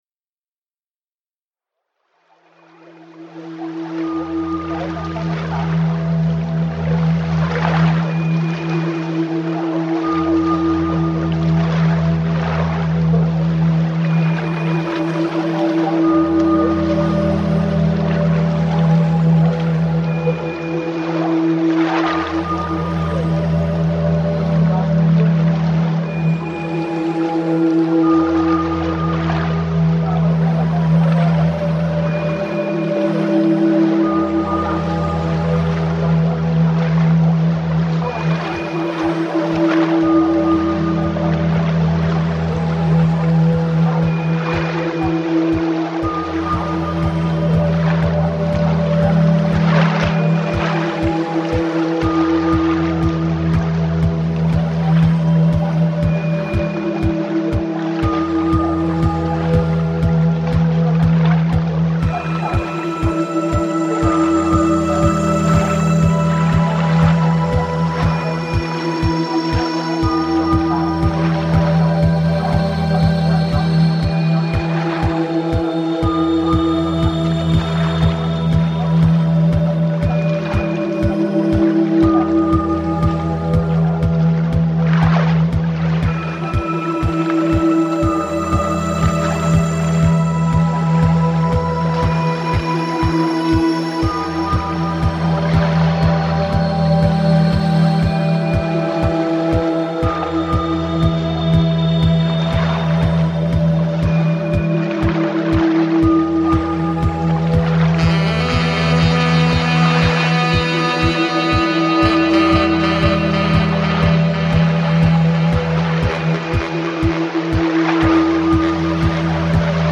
Giudecca waves in Venice reimagined